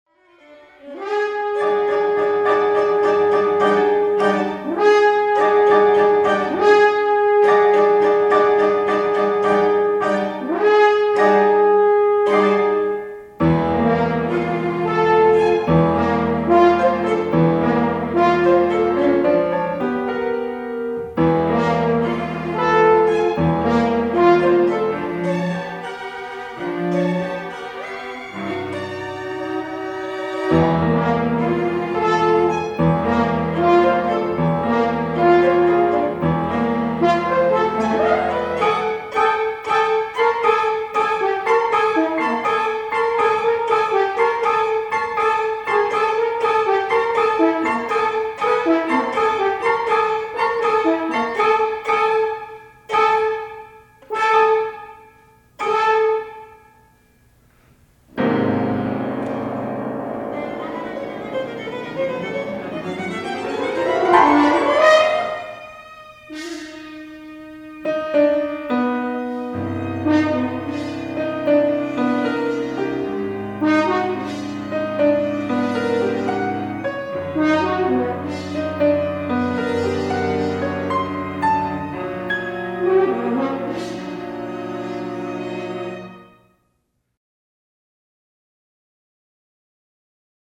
Chamber Ensembles
for violin, cello, piano and horn